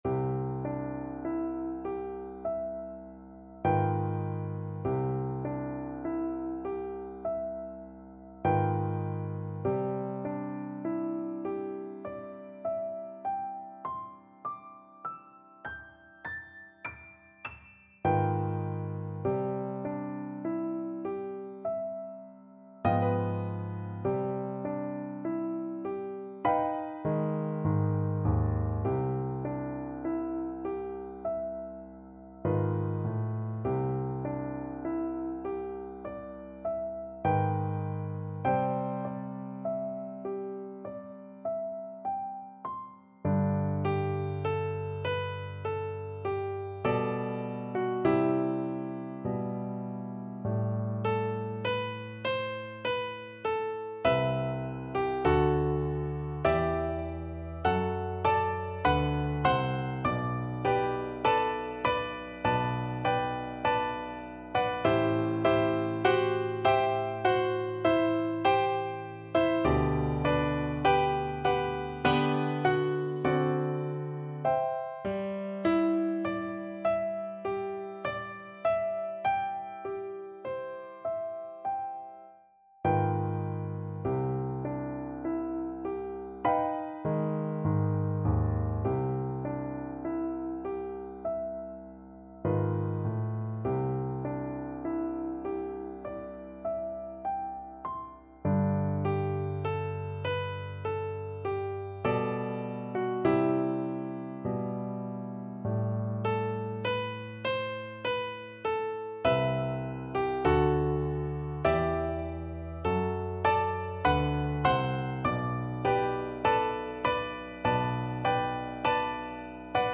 Classical (View more Classical Alto Recorder Music)